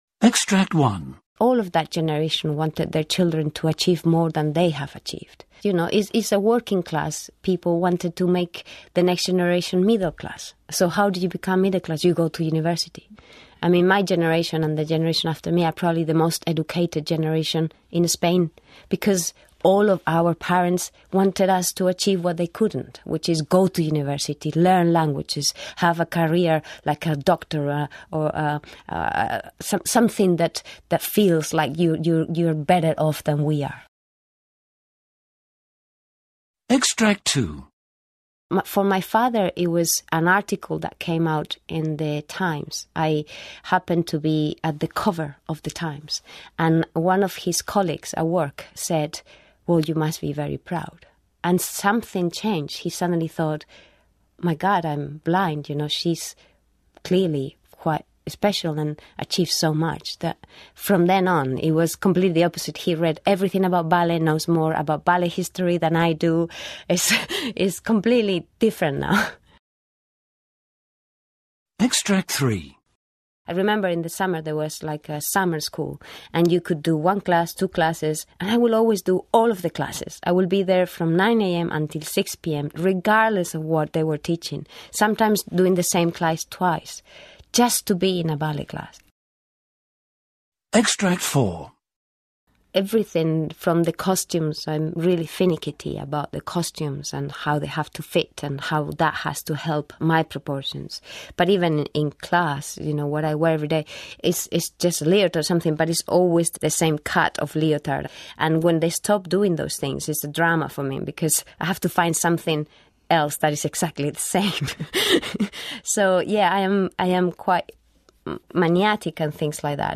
Interview with Tamara Rojo